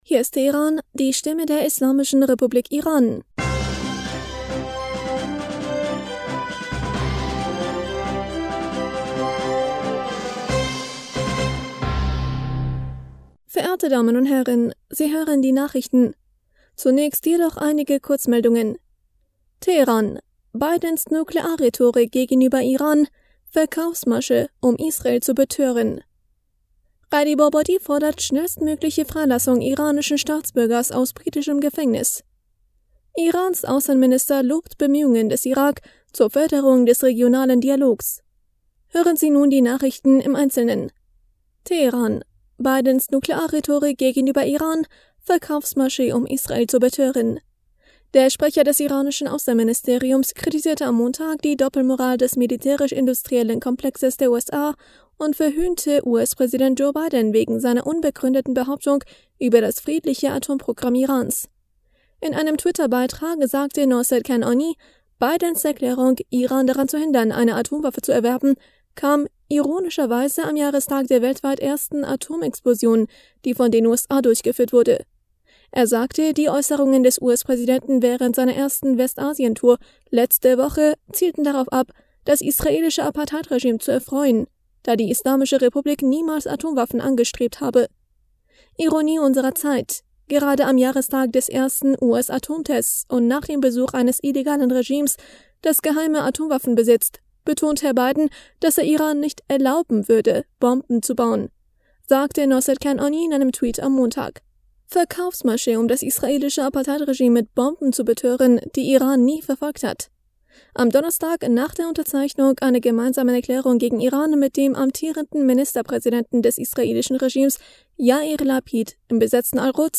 Nachrichten vom 18. Juli 2022